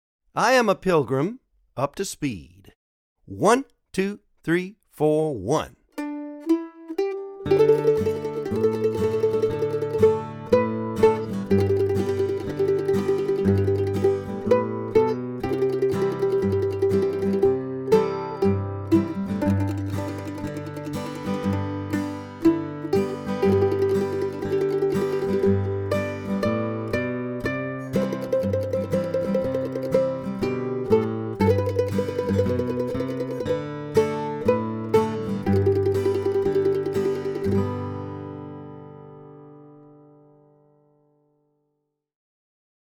DIGITAL SHEET MUSIC - MANDOLIN SOLO
(both slow and regular speed)